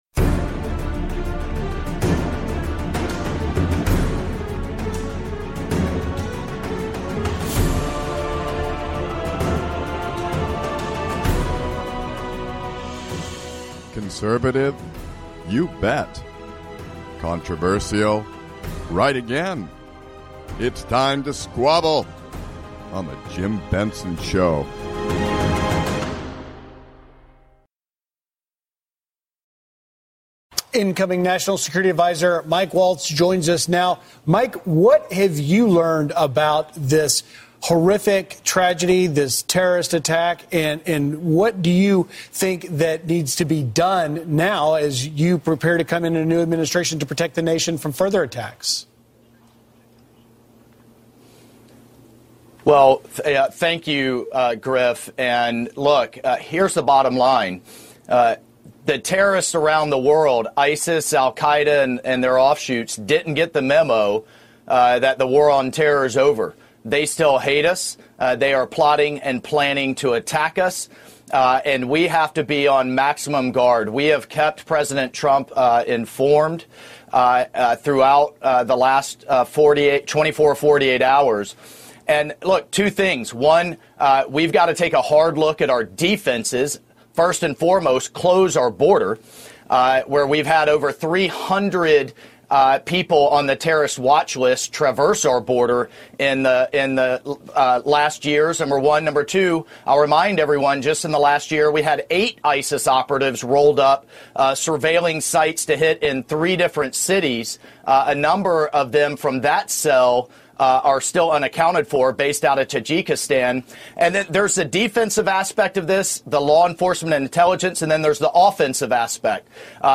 conservative talk radio done right, addressing the issues that concern you.
Talk Radio